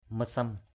/mə-sʌm/